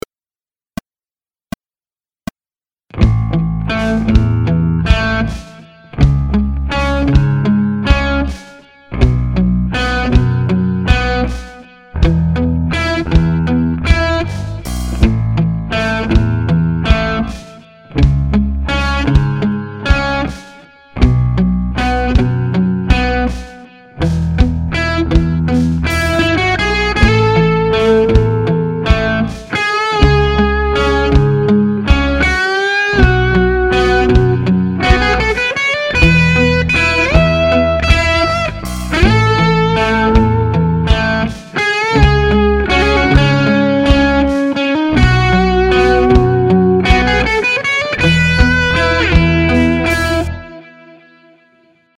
play along track